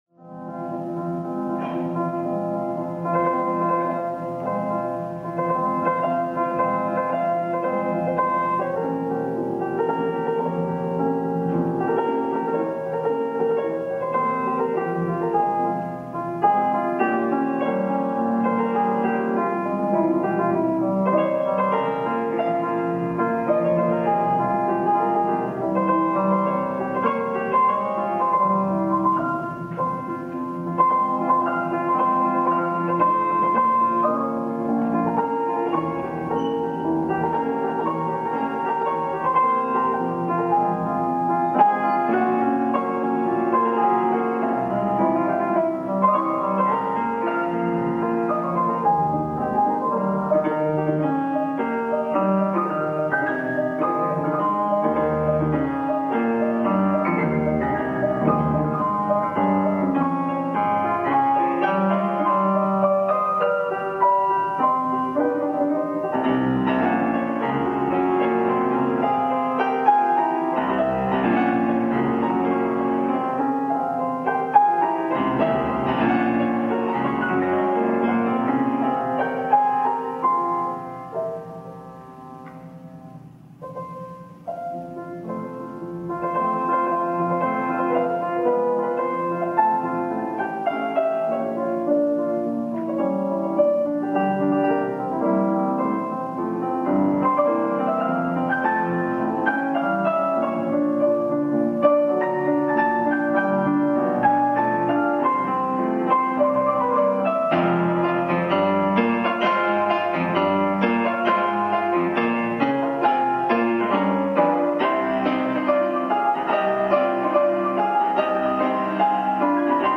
Franz Schubert – Dohnányi Ernő: f-moll fantázia zenekarra (f-moll négykezes zongorafantázia
Tallahassee